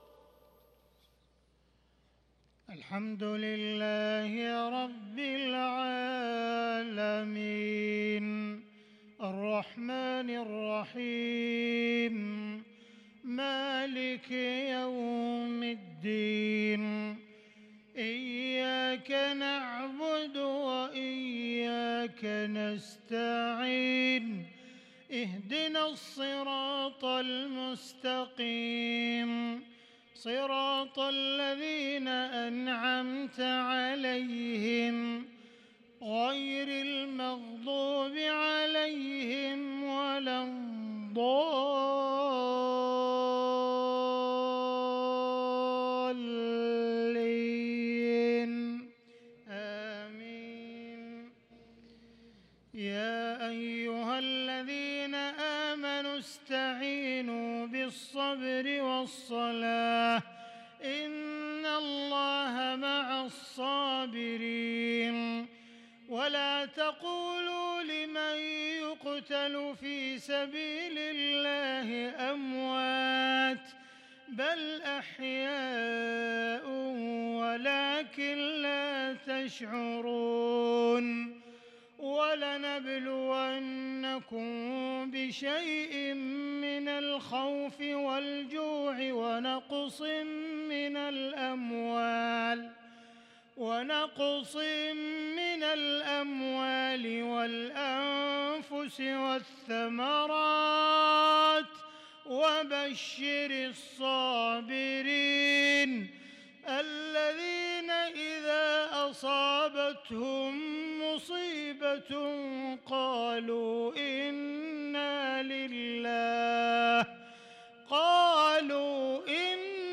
صلاة العشاء للقارئ عبدالرحمن السديس 18 ربيع الأول 1443 هـ
تِلَاوَات الْحَرَمَيْن .